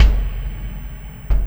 Kick Particle 11.wav